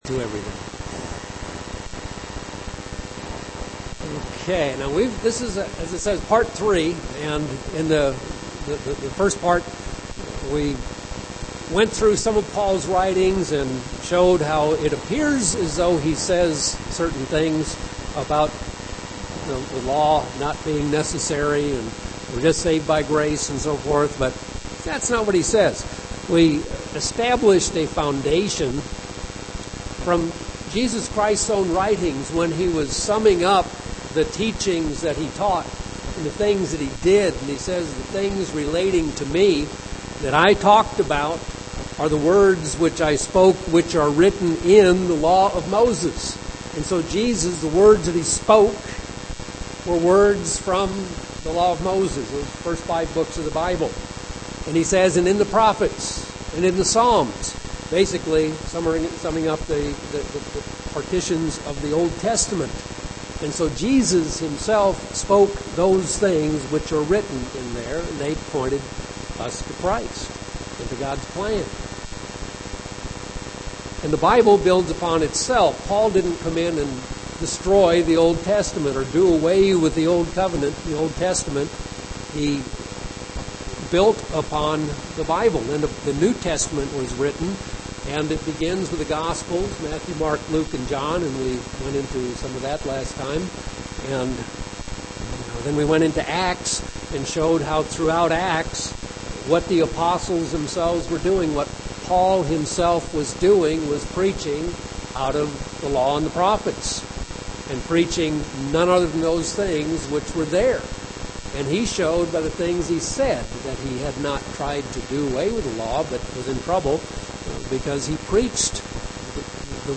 Bible Study: Understanding Paul's Writings Part 3